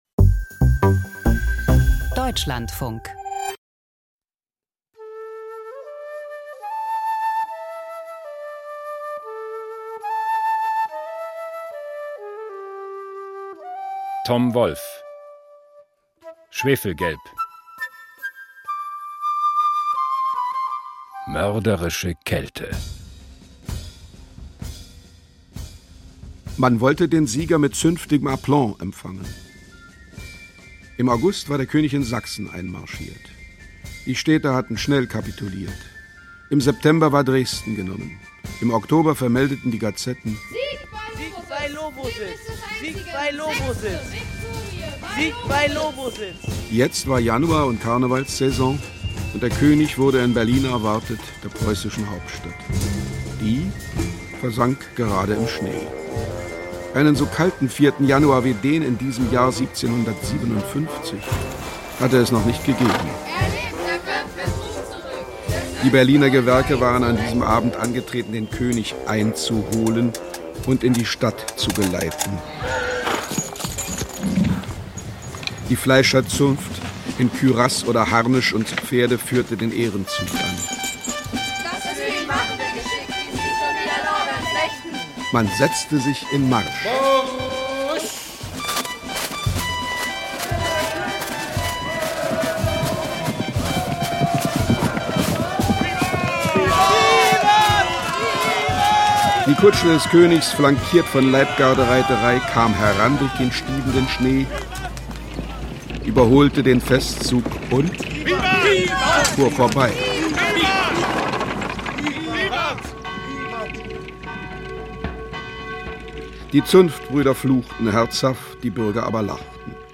Schwefelgelb – Krimi-Hörspiel von Tom Wolf